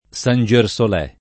vai all'elenco alfabetico delle voci ingrandisci il carattere 100% rimpicciolisci il carattere stampa invia tramite posta elettronica codividi su Facebook San Gersolè [ S an J er S ol $+ ] (raro Sangersolè [ id. ]) top. (Tosc.) — non con -é